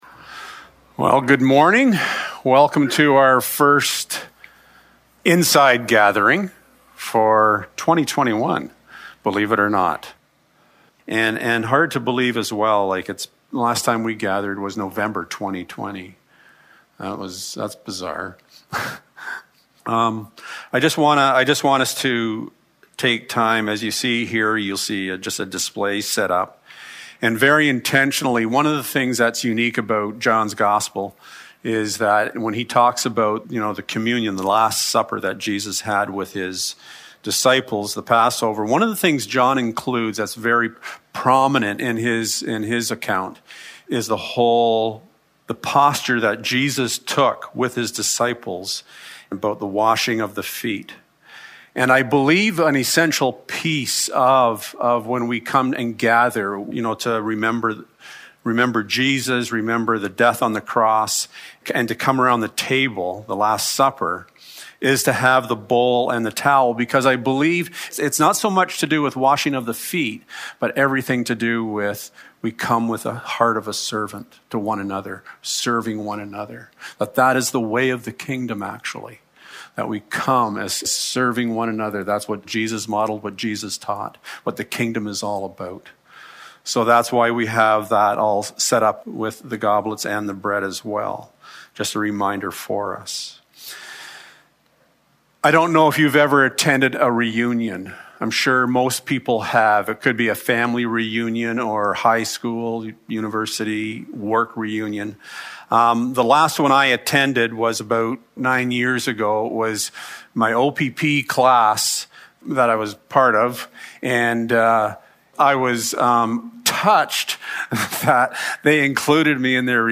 Re-member...Jesus Service Type: Sunday Morning Have you ever wondered how finite beings can adequately worship the infinite God of this universe?